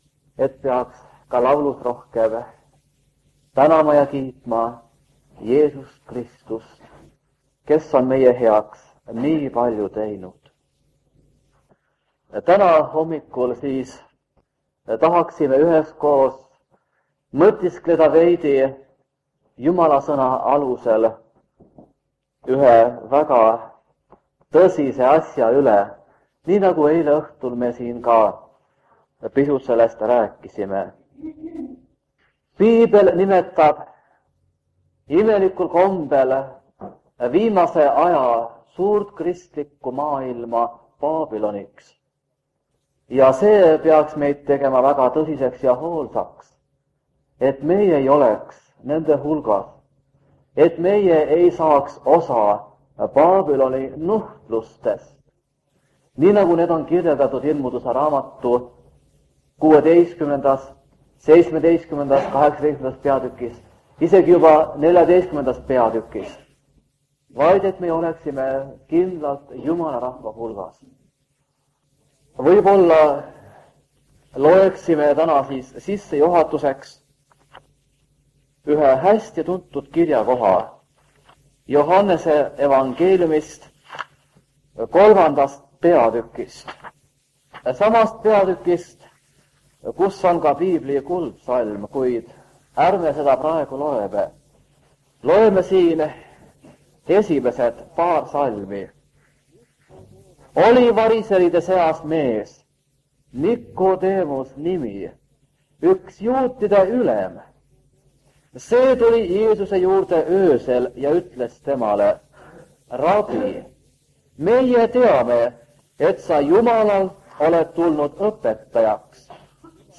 Jutlused
On 1978 aasta ja Kingissepa adventkoguduses on Evangeeliuminädal.